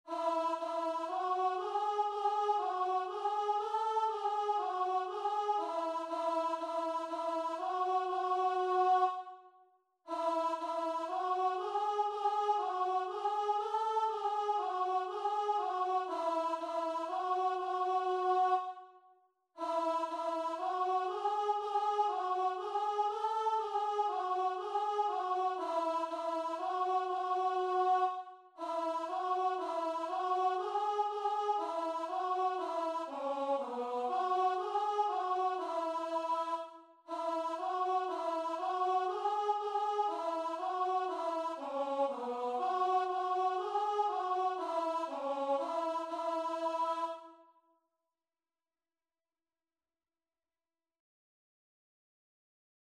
Free Sheet music for Voice
4/4 (View more 4/4 Music)
B4-A5
E major (Sounding Pitch) (View more E major Music for Voice )
Christian (View more Christian Voice Music)